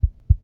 Heartbeat.ogg